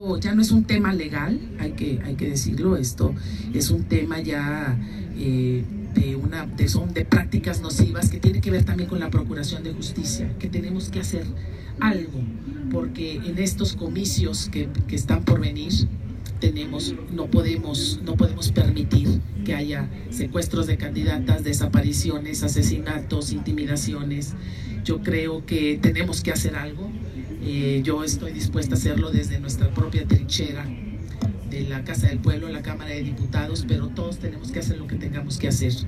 Al participar en la conmemoración del 70 aniversario del voto de las mujeres en México, que se llevó a cabo en el Tribunal Electoral del Poder Judicial de la Federación (TEPJF), la legisladora priista, dijo que la advertencia se encuentra en la violencia, en los feminicidios, en el asesinato de candidatas, en la intimidación a las que están sujetas.